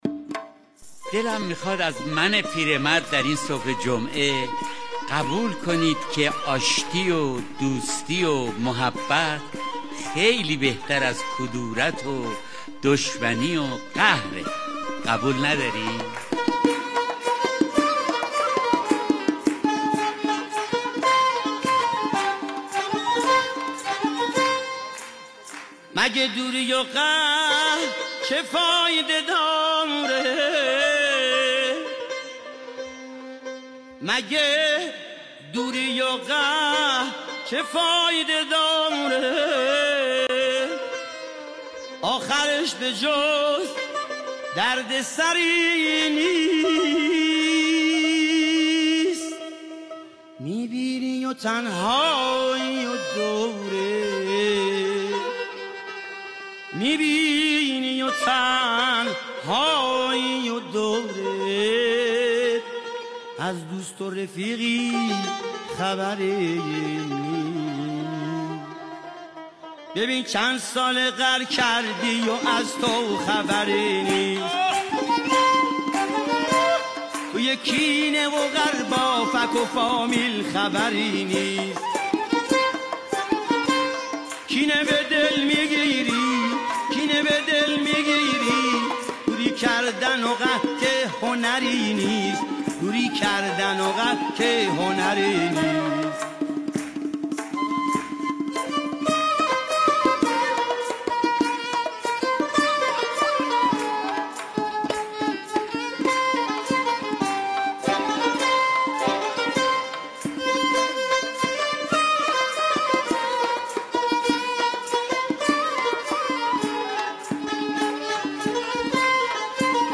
• برنامه طنز جمعه ایرانی هر جمعه ساعت 9 تا 11:30 از رادیو ایران